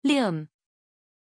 Pronunciation of Lyam
pronunciation-lyam-zh.mp3